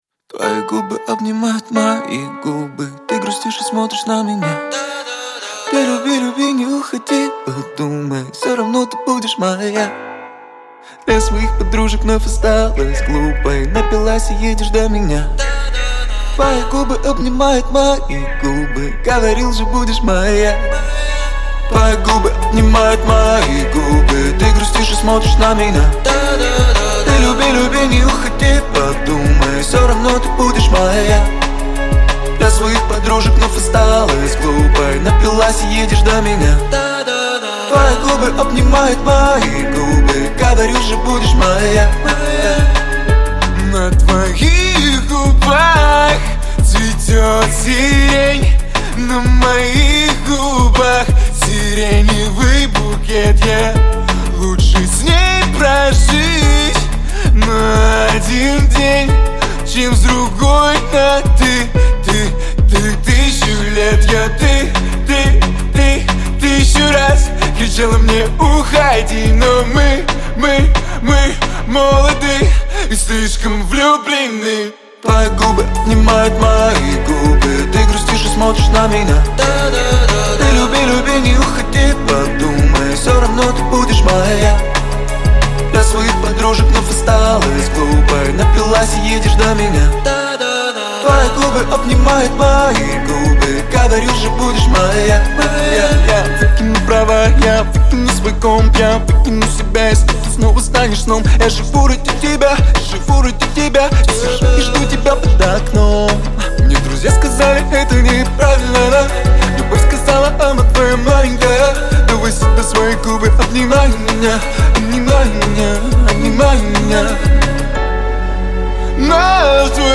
Поп-музыка
Жанр: Поп-музыка / Хип-хоп / Русский рэп